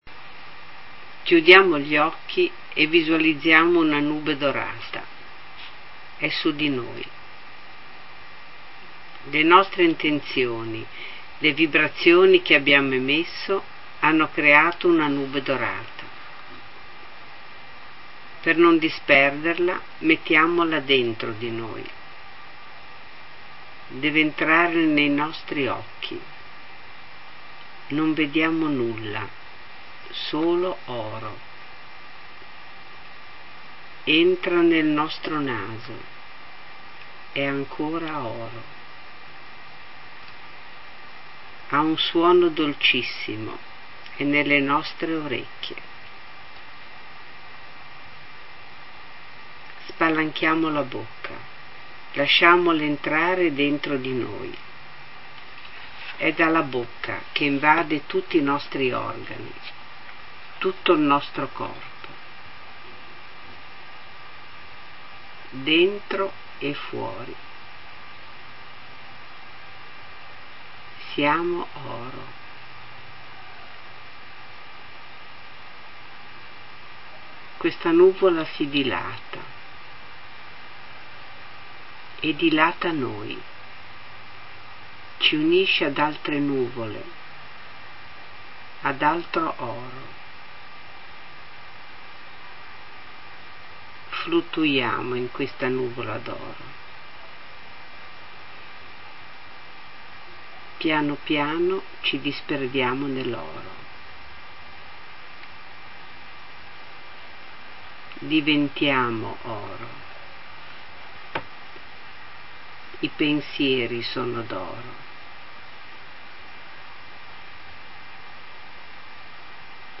Nube Dorata – meditazione